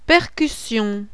A PERCUSSION.wav